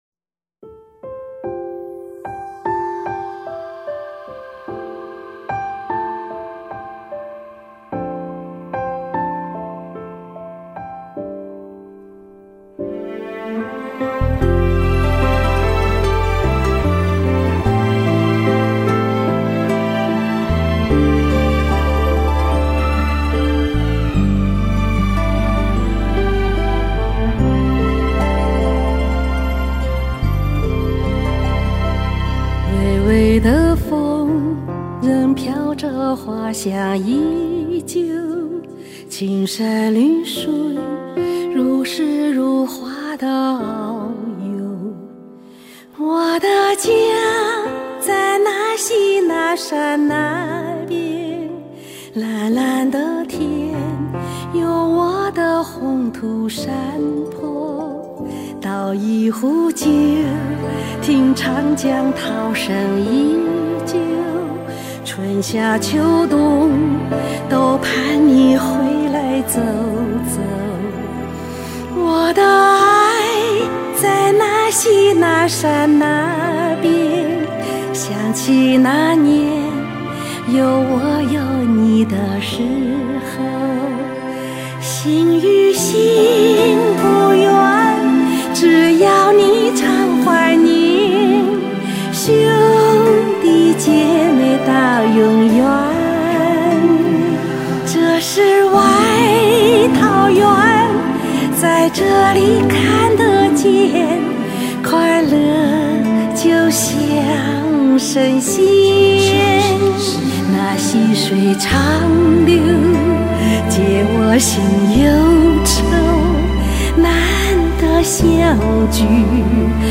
旋律婉转而深情